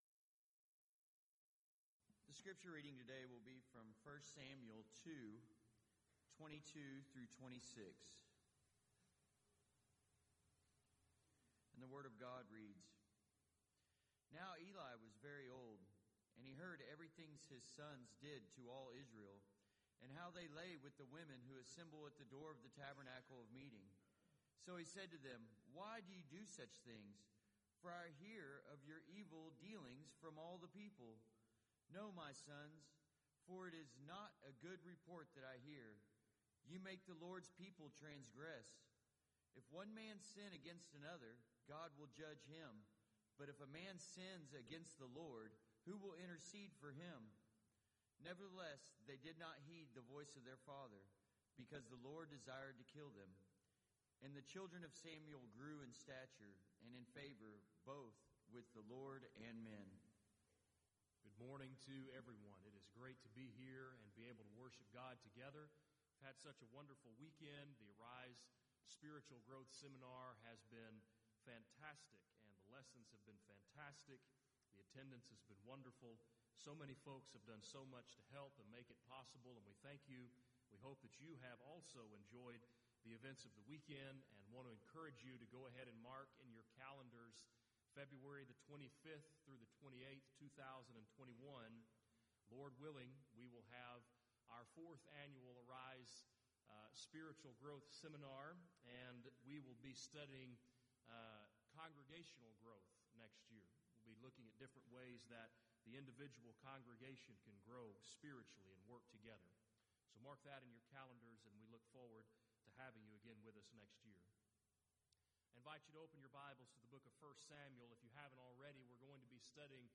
Event: 3rd Annual Southwest Spritual Growth Workshop Theme/Title: Arise and Grow as a Family
lecture